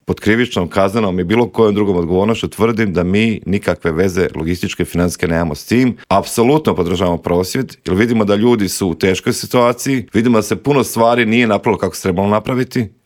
ZAGREB - U Intervjuu Media servisa gostovao je Mario Radić iz Domovinskog pokreta koji se osvrnuo na optužbe premijera Andreja Plenkovića da iza prosvjeda svinjogojaca stoji upravo njegova stranka, otkrio nam s kim bi DP mogao koalirati nakon parlamentarnih izbora, a s kim nikako i za kraj rezimirao 2023. godinu koja lagano ide kraju.